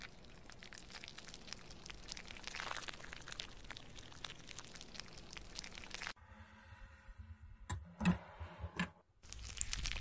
Kling-Audio-Eval / Human sounds /Hands /audio /22487.wav